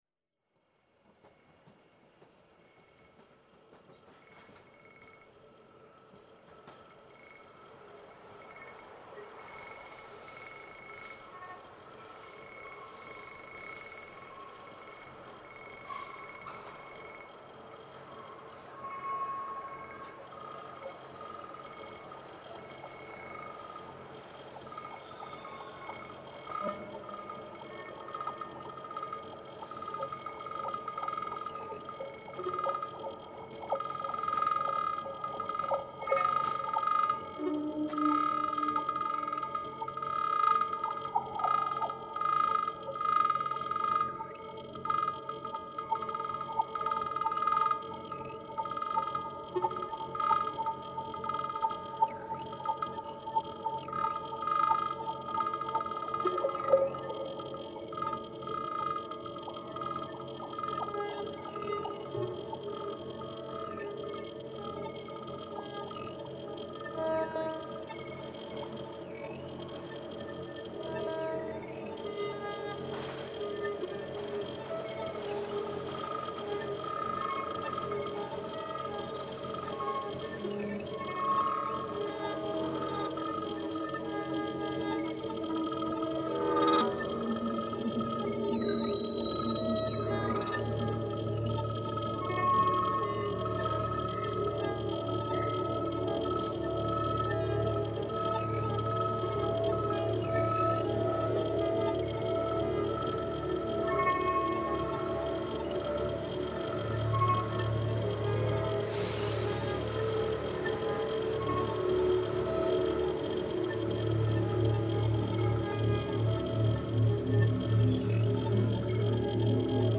Actually, it mostly just sounds like multitracked noodling.